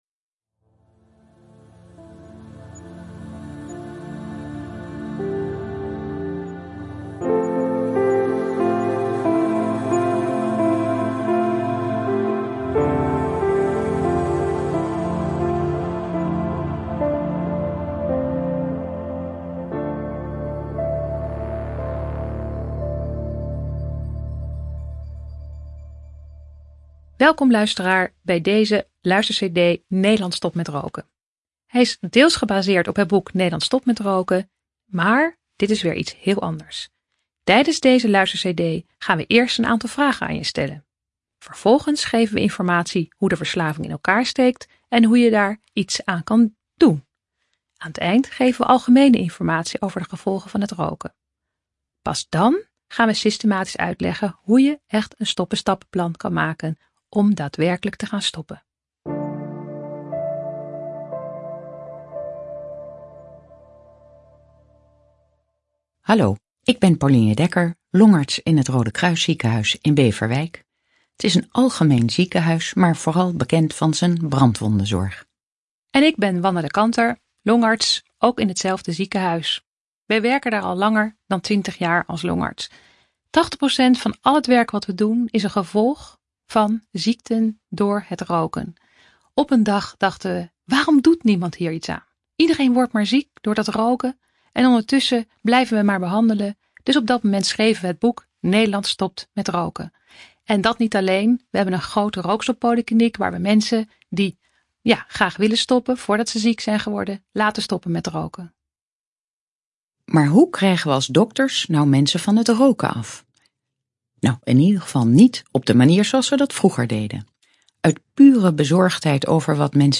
Nederland stopt met roken - luisterversie
U kunt het boek hieronder ook beluisteren.